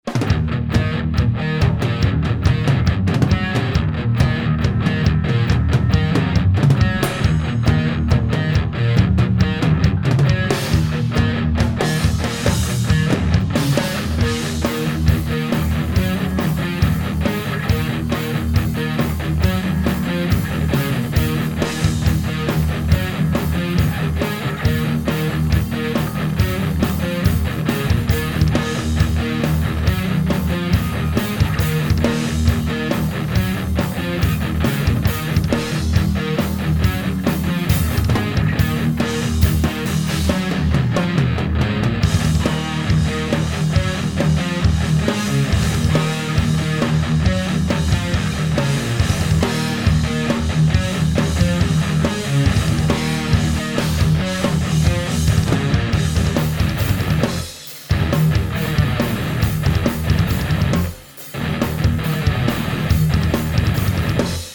Pre vsetkych priaznivcov internetoveho mudrovania a pocuvania a porovnavania ukazok som pripravil 6 vzoriek kvazy toho isteho - 2 beglajtove gitary + basa + bicie. Rozdiel je iba v gitarach, boli pouzite viacere gitarove aparaty alebo modeling.